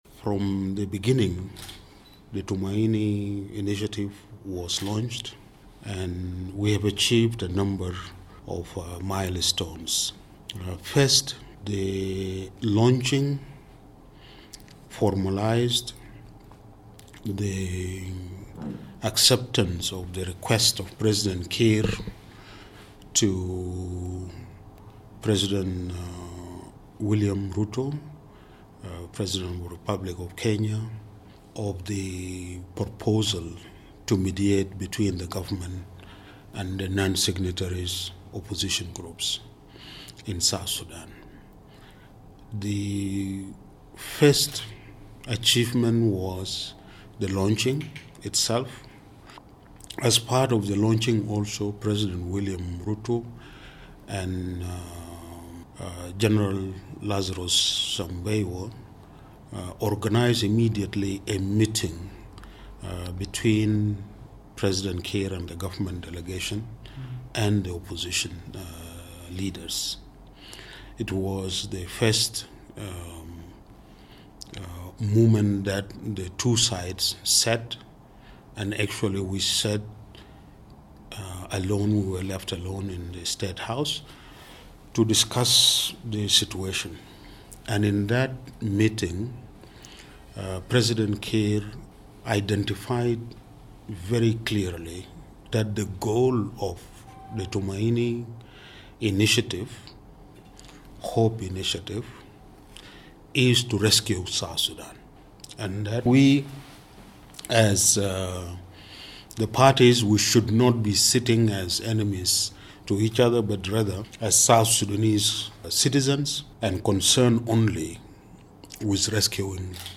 in Nairobi, on the sidelines of the high-level mediation talks between the government, opposition hold-out groups and stakeholders.